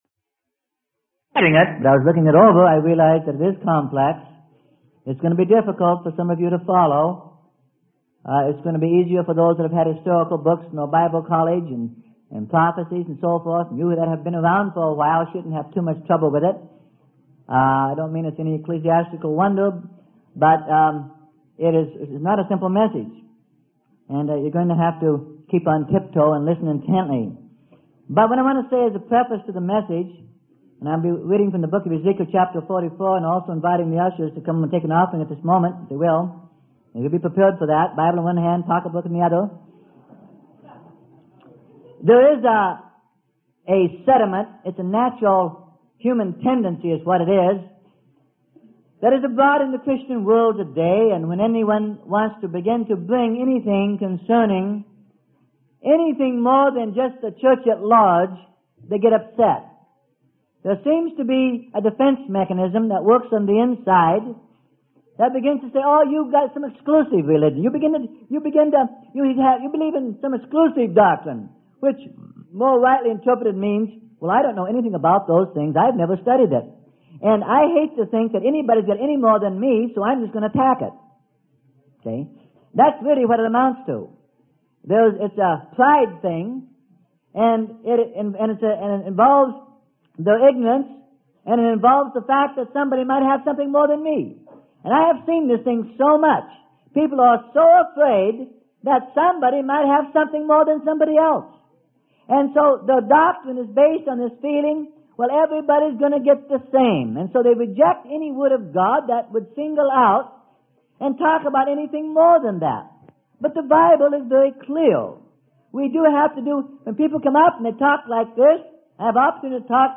Sermon: Becoming Zadokite Priests - Part 1 - Freely Given Online Library